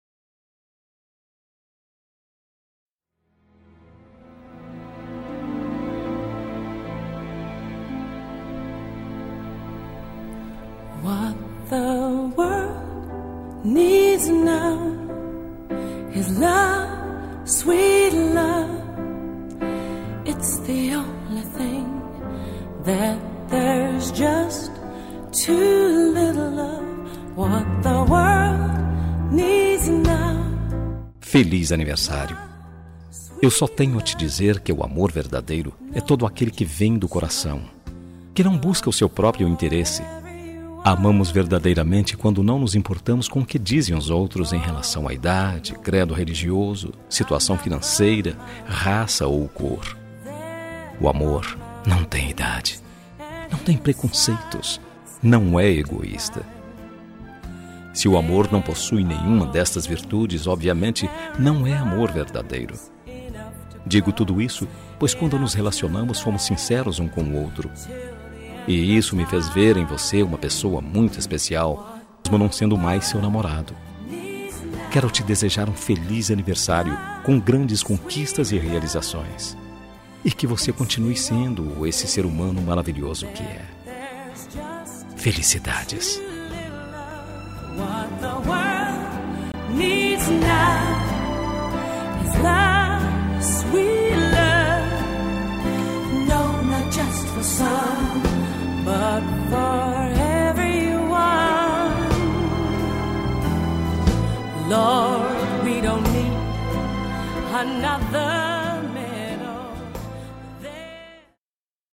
Telemensagem de Aniversário de Ex. – Voz Masculina – Cód: 1379